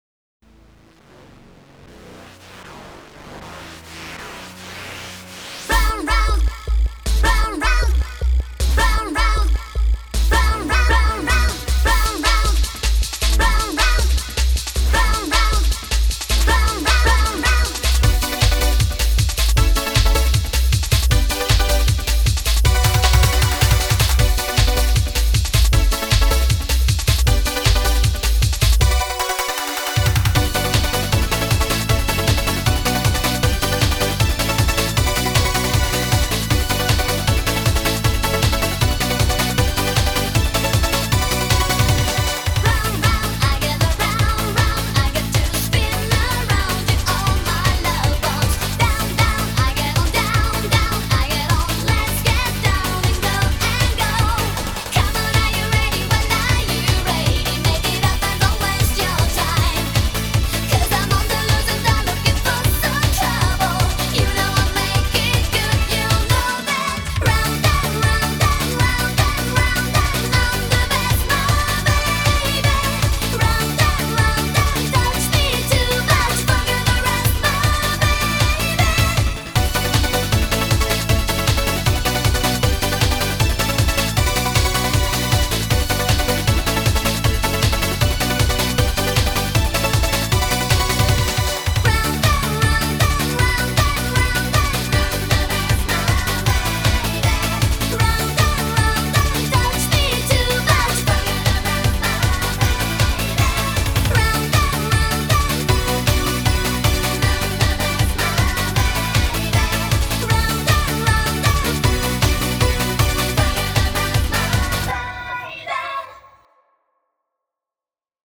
BPM156